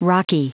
Prononciation du mot rocky en anglais (fichier audio)
Prononciation du mot : rocky